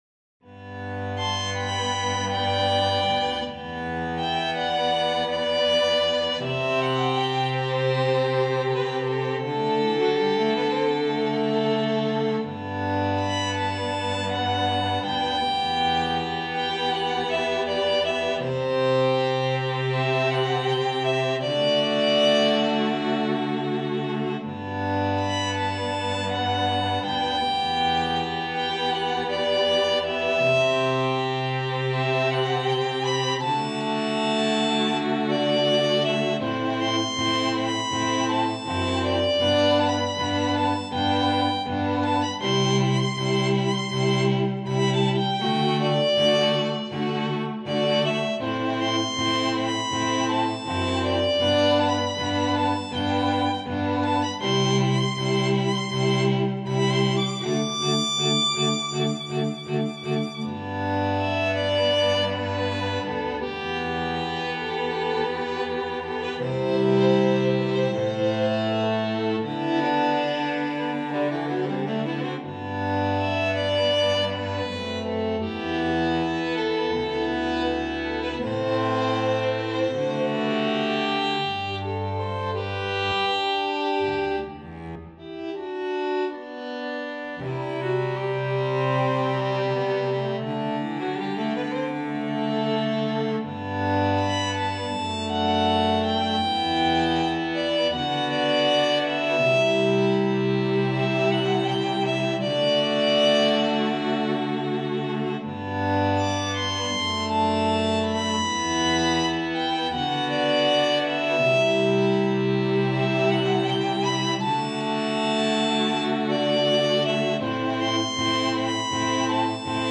para Quarteto de Cordas
● Violino I
● Violino II
● Viola
● Violoncelo